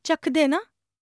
TALK 9.wav